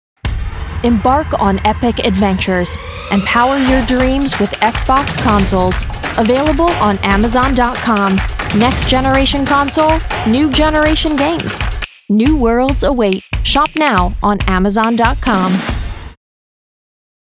hell, fein, zart, sehr variabel
Commercial (Werbung)